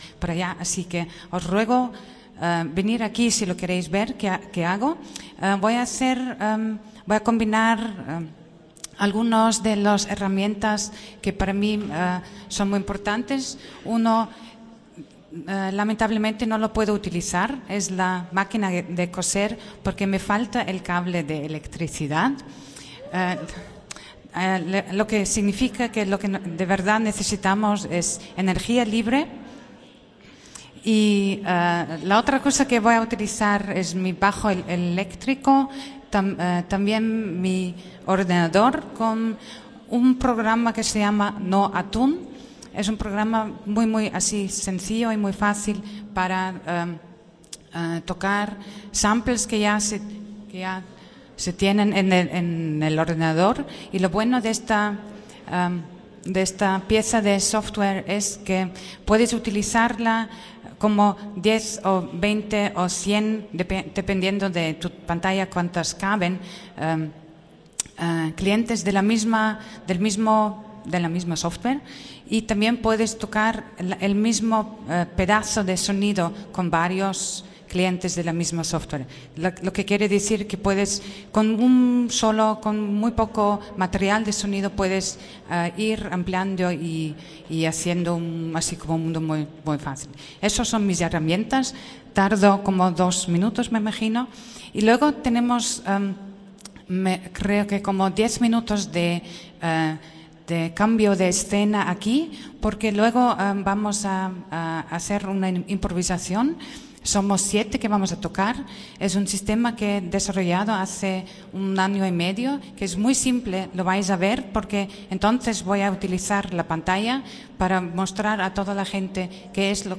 VideoMitschnitt/videorecording E-Bass, Cello-Bogen, Nähmaschine, Laptop, Weltempfänger electric bass, cello bow, sewing machine, laptop, world receiver